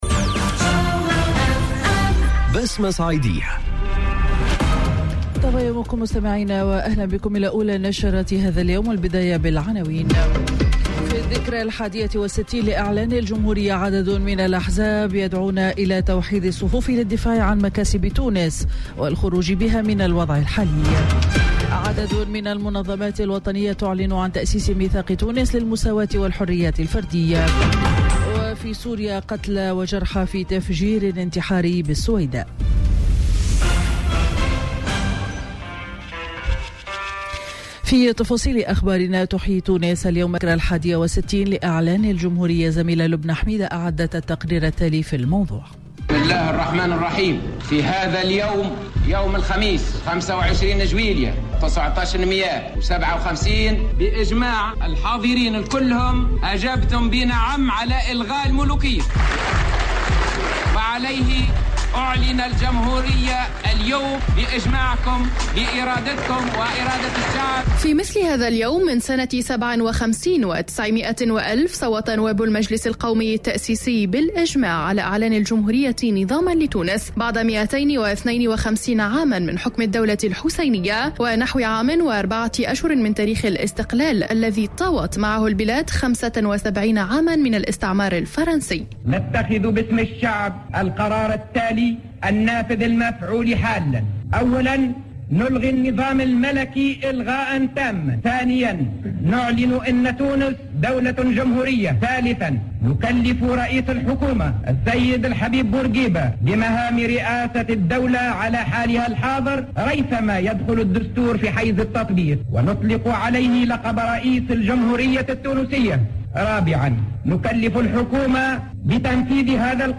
نشرة أخبار السابعة صباحا ليوم الإربعاء 25 جويلية 2018